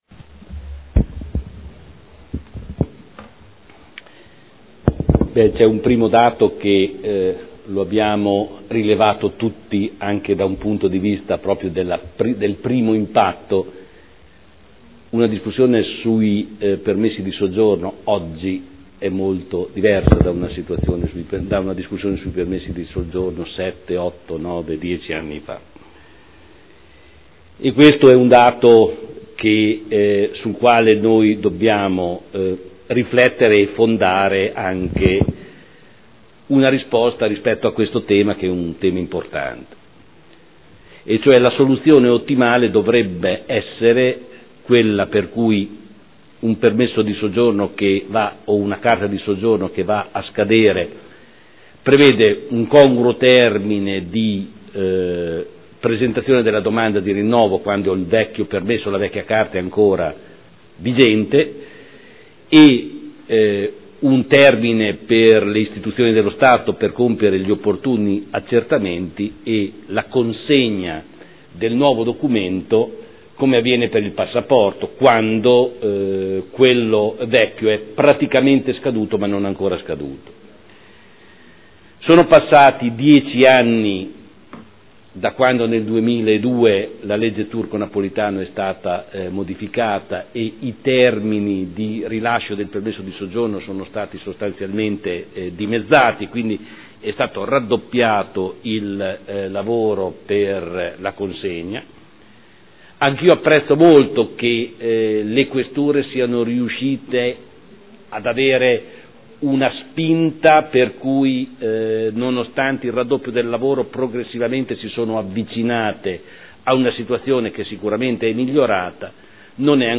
Giorgio Pighi — Sito Audio Consiglio Comunale